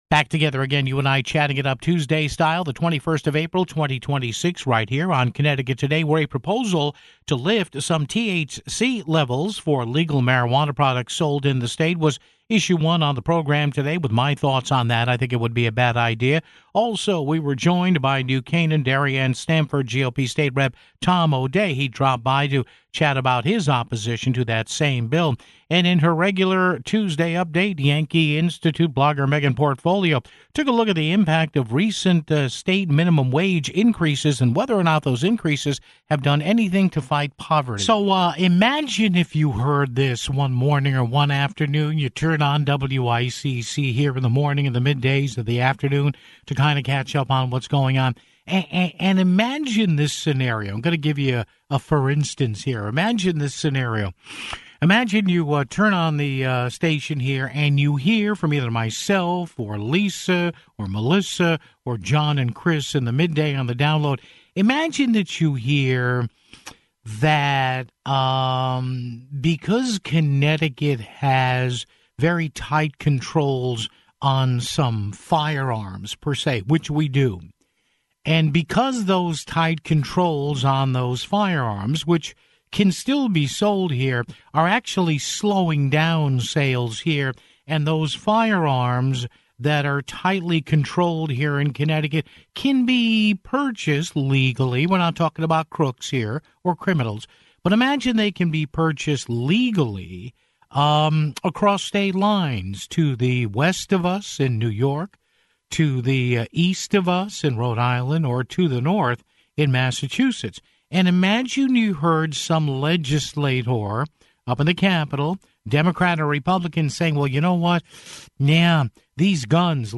New Canaan / Darien / Stamford GOP State Rep. Tom O'Dea dropped by to chat about his opposition to that proposal (14:18).